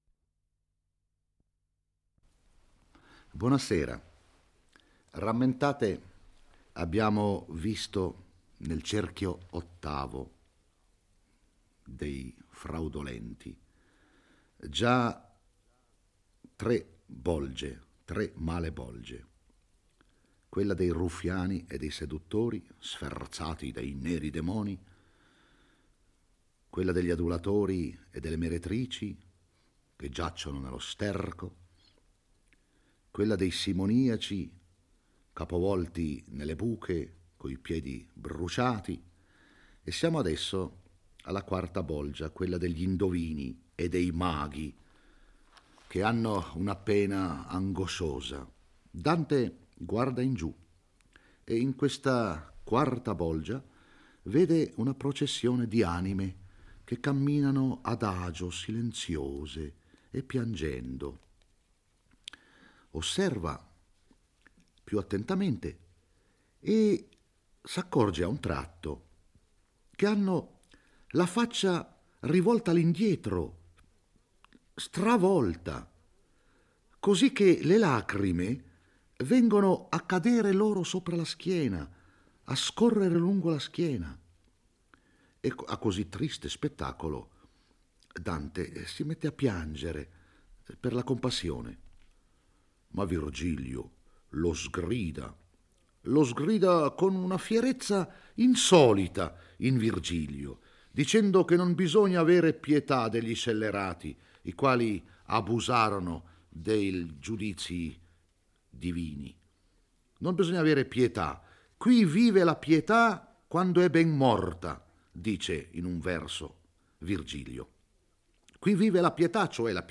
legge e commenta il XX canto dell'Inferno. Qui, Dante e Virgilio giungono alla quarta bolgia dell'ottavo cerchio, dimora dei maghi e degli indovini.